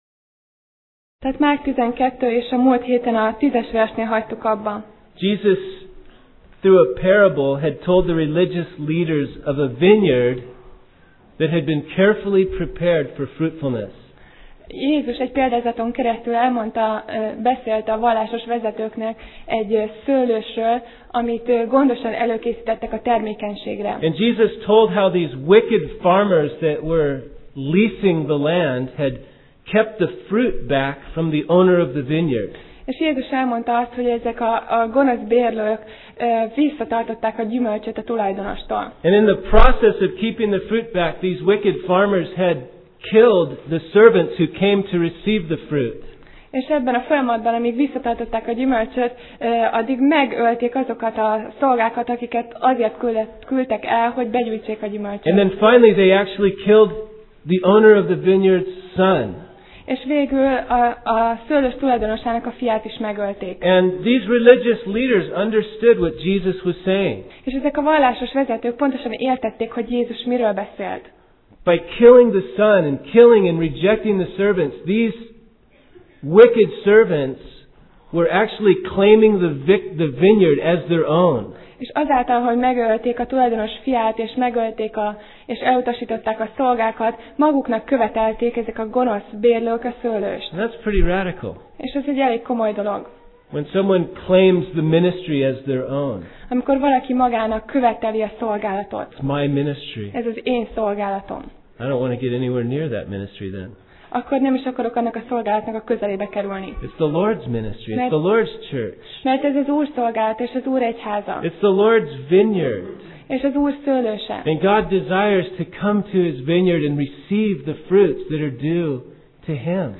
Márk Passage: Márk (Mark) 12:10-27 Alkalom: Vasárnap Reggel